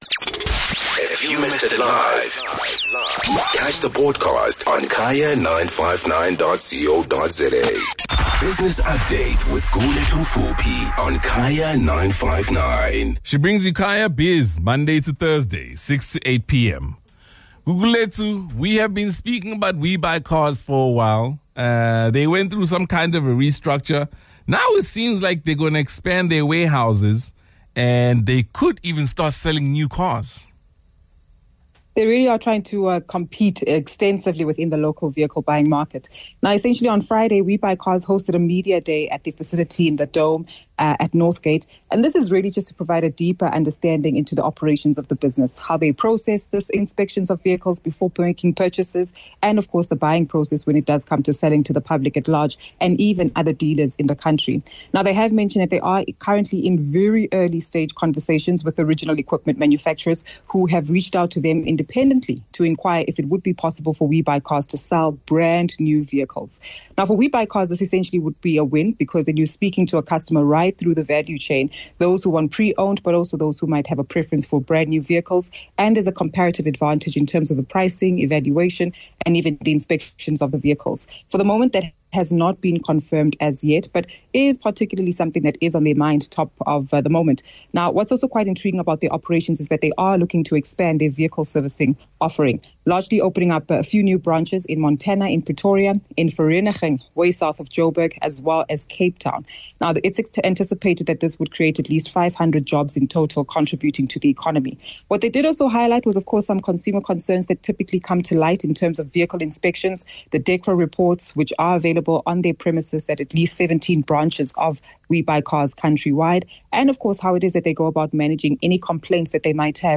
23 Jun Business Update - We Buy Cars expansion & Oil prices potential surge